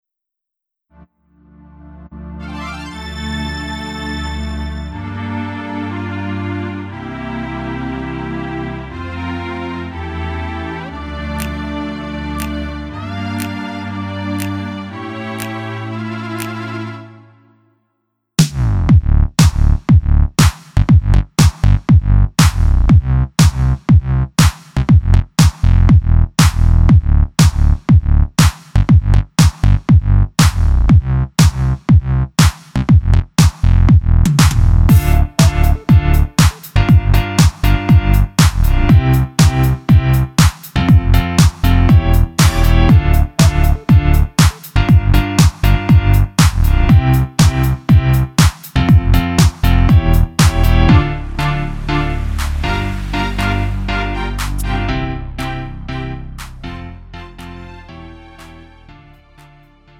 음정 -1키 2:46
장르 가요 구분 Lite MR